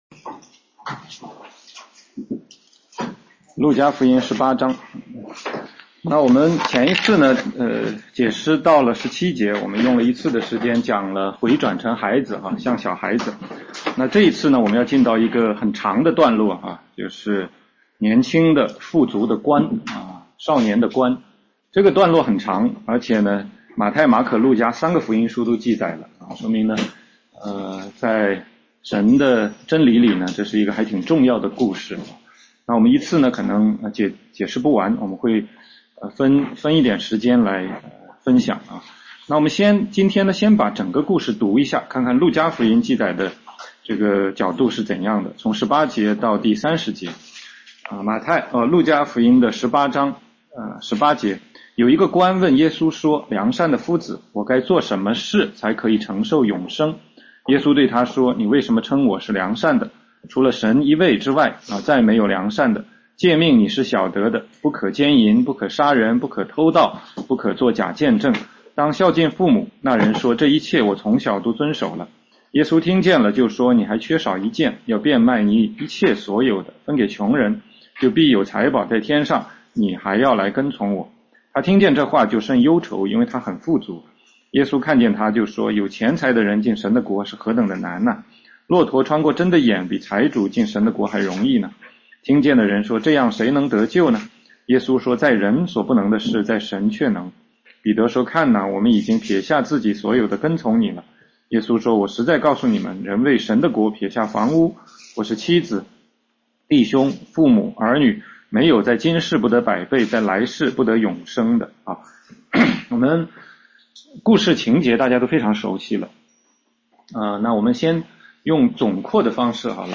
16街讲道录音 - 路加福音18章18-23节：富有的官（1）
全中文查经